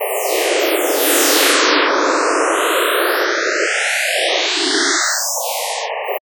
Coagula is a bitmap to sound converter.
With the 'Echord tool' one can apply an echo of the bitmap